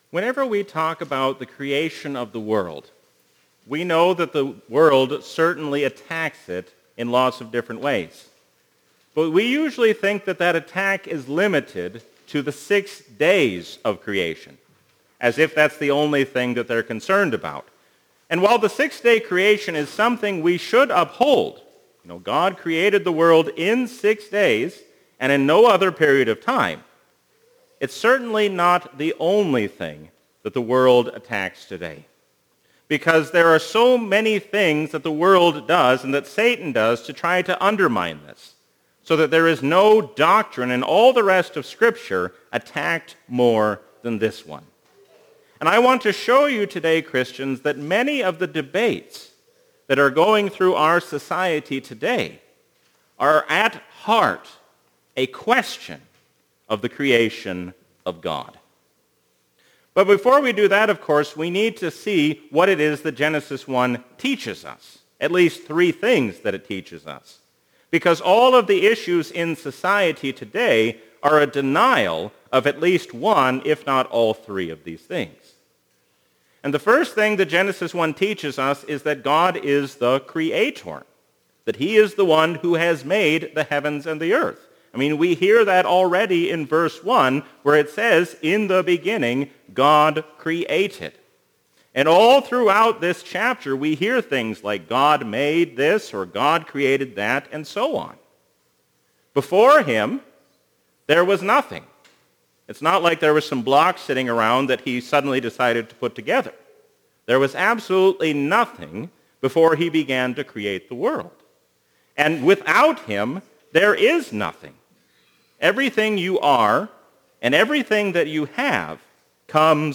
Sermons – St. Peter and Zion Lutheran